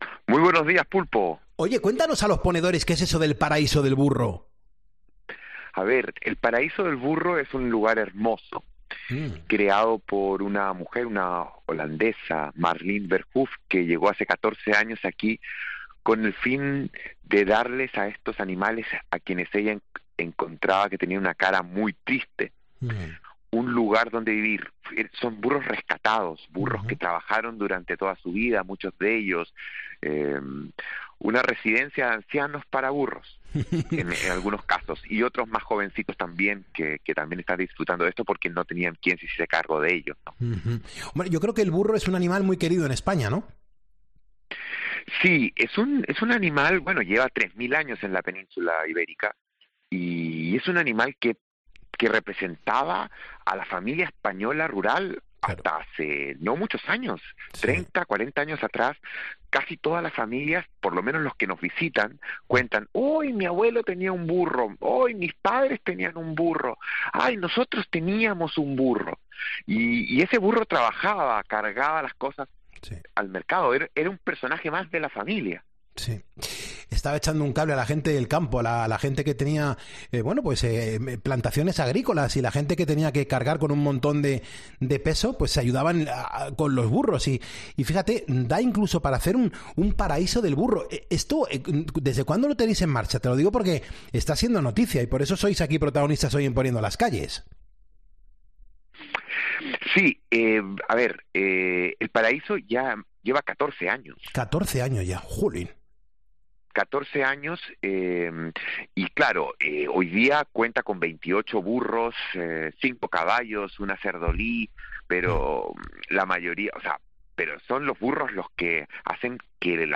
- Entrevistas en Poniendo las calles - COPE